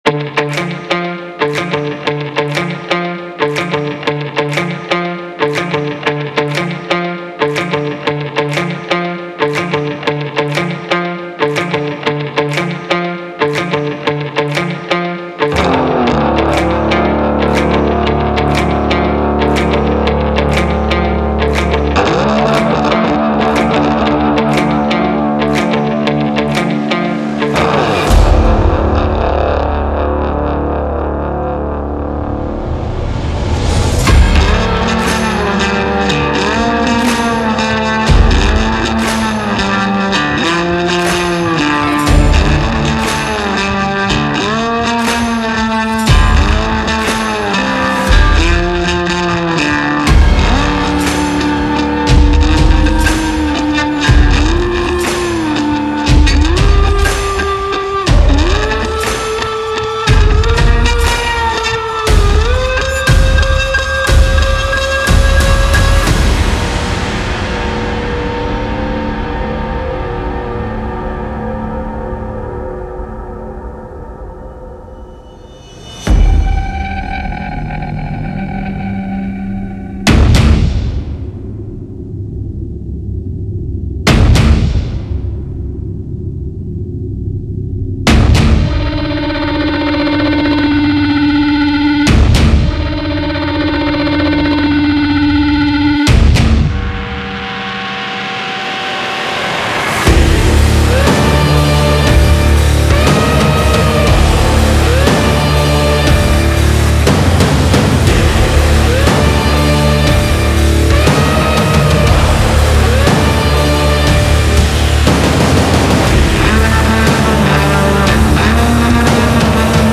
Epic dark rock Instrumental